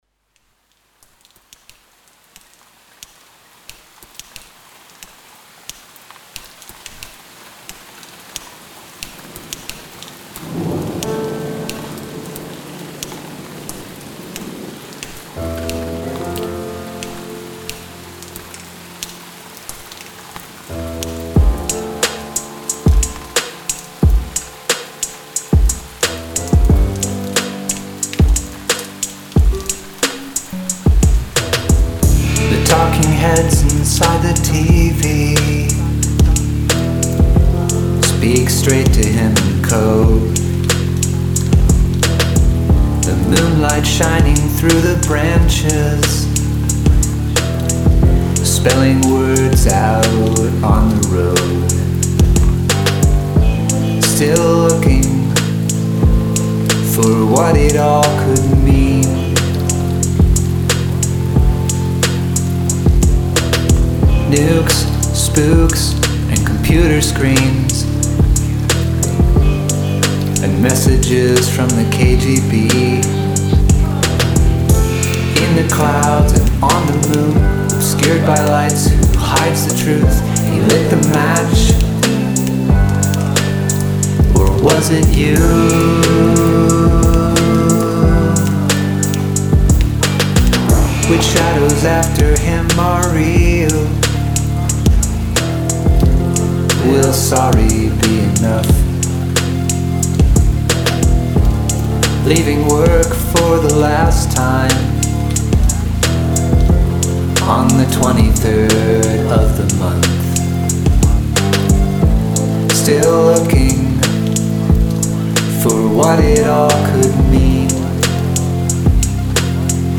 Use of field recording
Sounded spooky, so we threw it in.
The storm sounds ensure it is at least checked off, though.